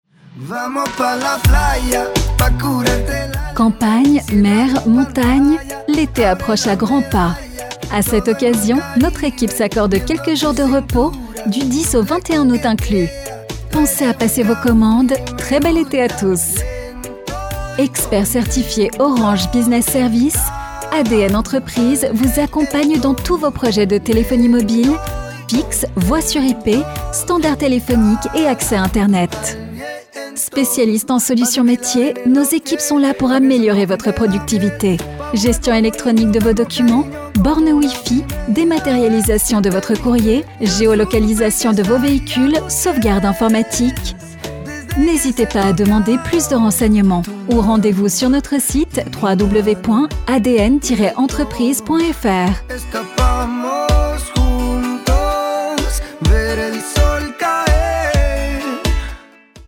Votre texte d’accueil sera quant à lui prononcé par un vrai comédien (homme ou femme selon votre choix) et personnalisé avec votre nom ainsi que celui de votre entreprise, dans la langue que vous souhaitez.
Pour un authentique design musical, vos messages vocaux sont enregistrés dans un vrai studio d’enregistrement et mixés par une équipe de professionnels du son (directeur artistique, compositeurs, ingénieurs du son).
• Messagerie vocale personnalisée téléphonie mobile
adn-entreprise_demo-attente-ete.mp3